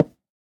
Minecraft Version Minecraft Version latest Latest Release | Latest Snapshot latest / assets / minecraft / sounds / block / cherry_wood / break5.ogg Compare With Compare With Latest Release | Latest Snapshot